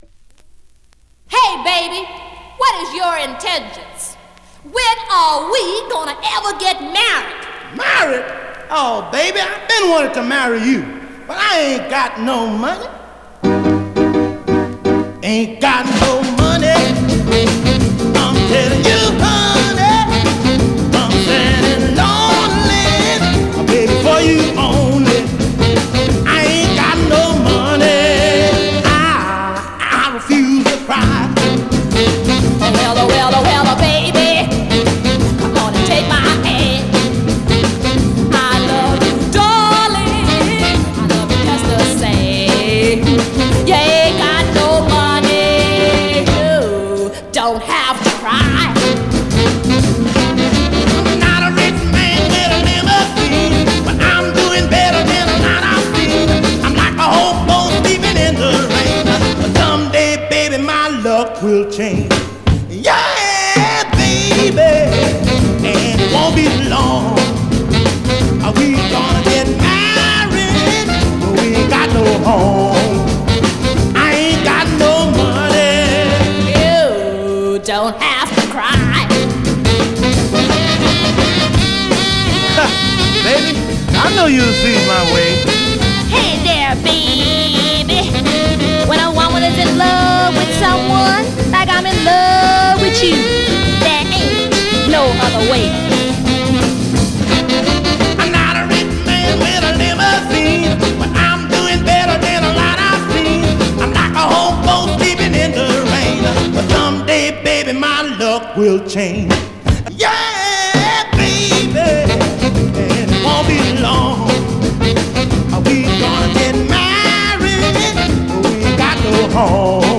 Great up-tempo Rnb / Mod
Category: R&B, MOD, POPCORN